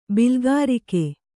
♪ bilgārike